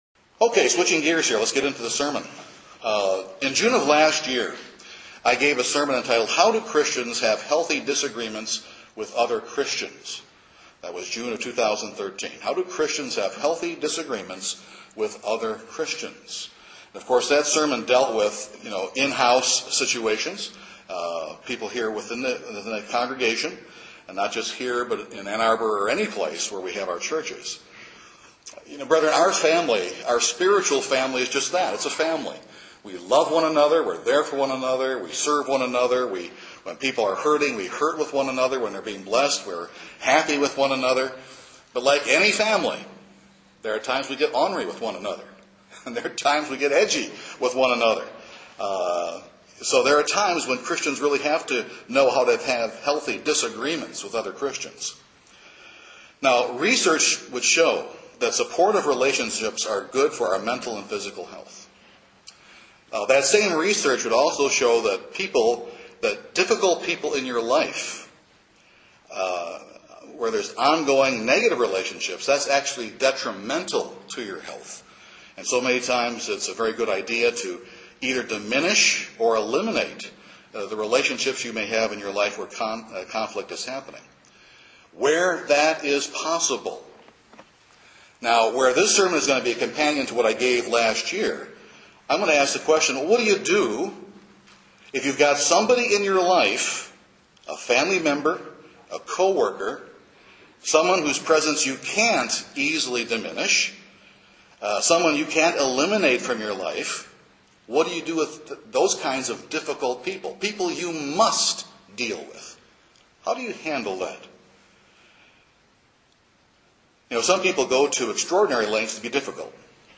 This sermon gives practical biblical guidelines that will give you some relief from the stresses you may currently be under.